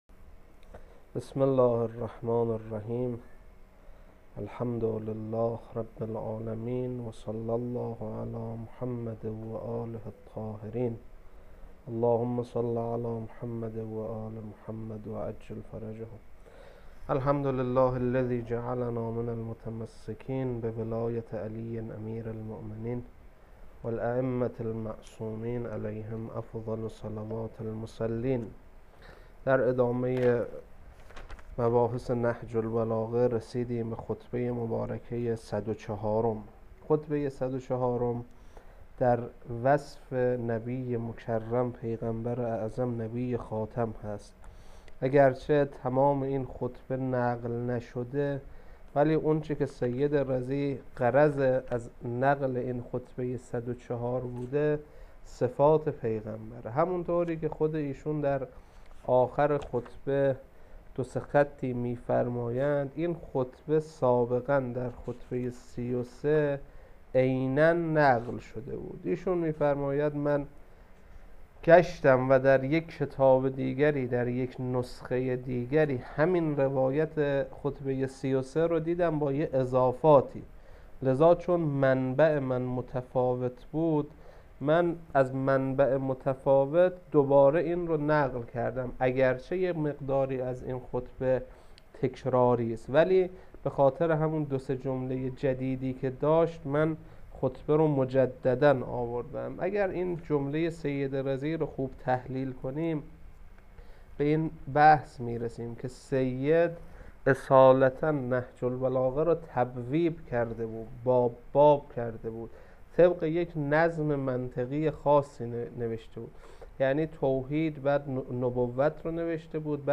خطبه 104
خطبه-104.wma